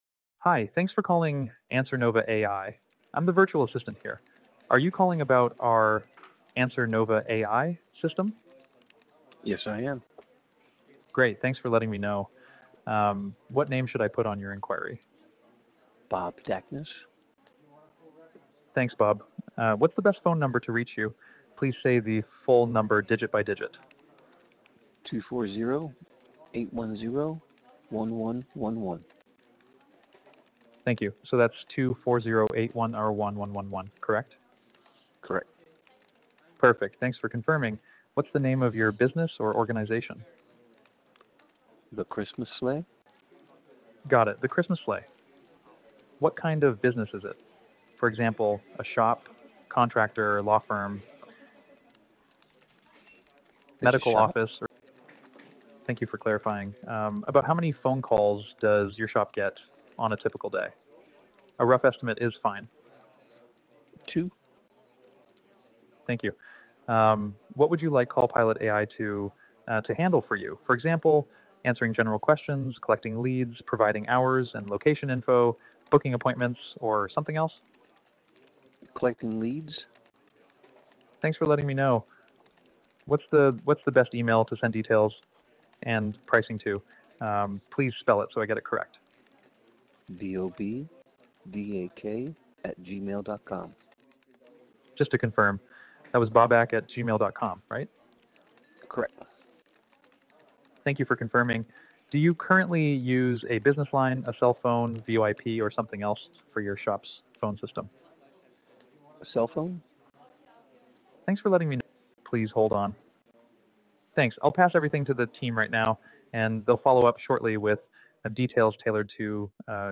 Answer NOVA behaves like a calm, friendly receptionist—just one that works 24/7 and never forgets to ask for a name, number, and reason for calling.